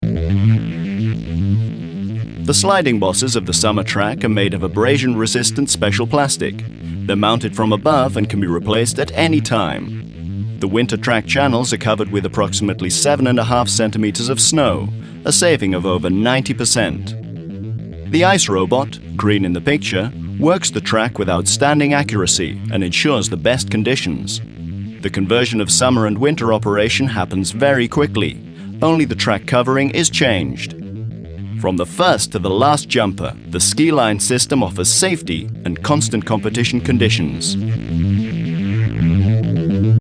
Baujahr 1968: Eine jugendhafte, klare, ausdrucksvolle Stimme.
His German pronunciation is excellent with a hint of a British accent.
Sprecher britisch - englisch.
Sprechprobe: Werbung (Muttersprache):